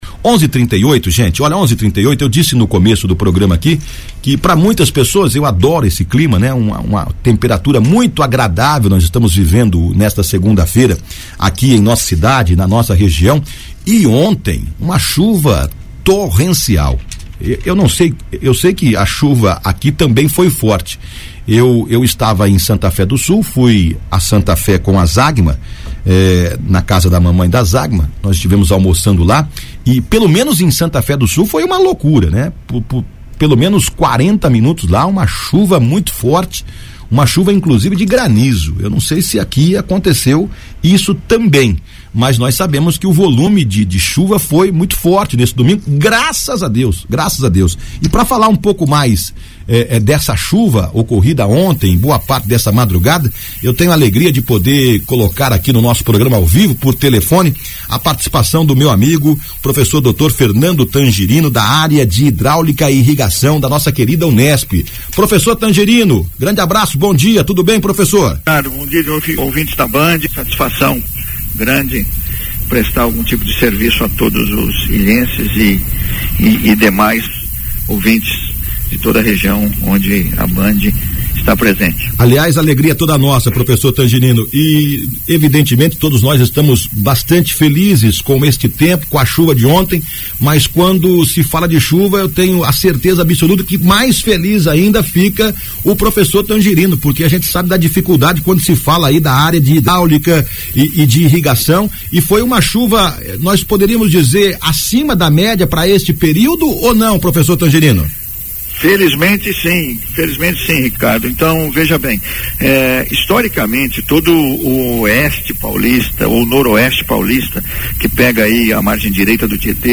A queda na temperatura e um clima mais gostoso também fizeram parte do bate papo. Ouça aqui a entrevista completa